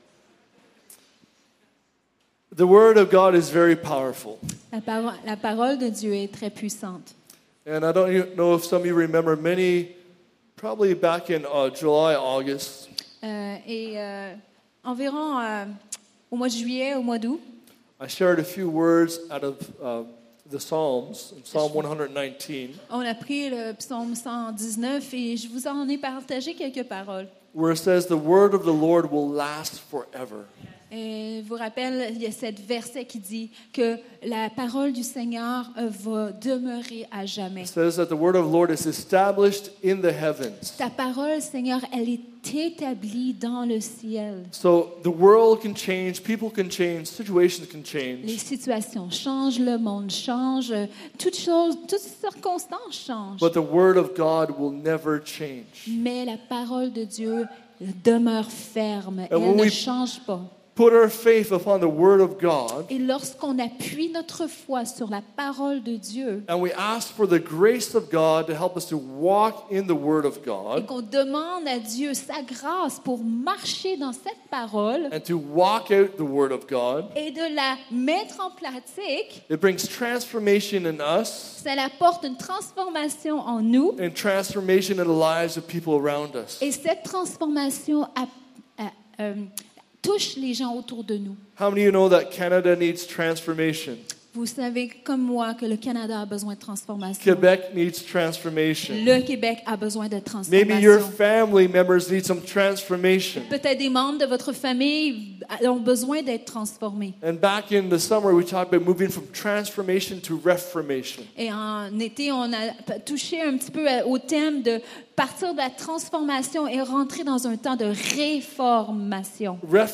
Sermons | Evangel Pentecostal Church